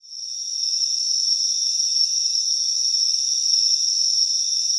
ATMOPAD26 -LR.wav